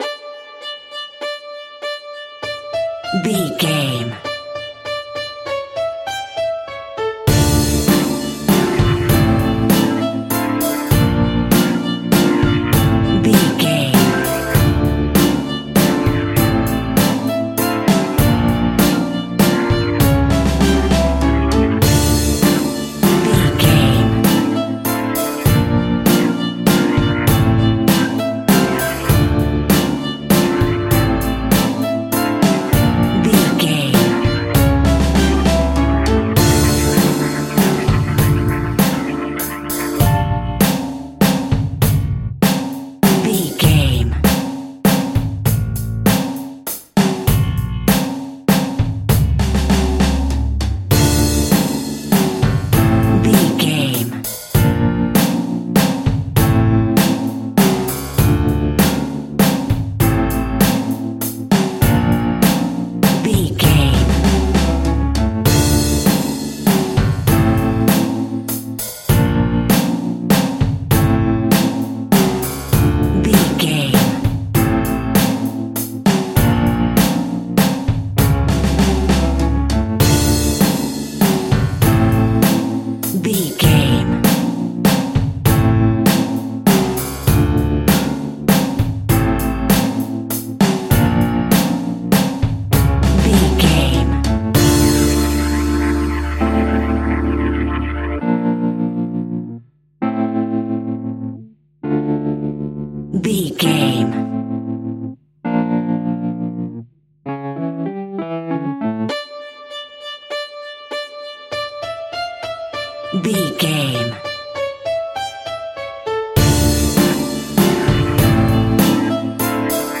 Aeolian/Minor
tension
ominous
dark
eerie
electric guitar
violin
piano
strings
bass guitar
drums
percussion
horror music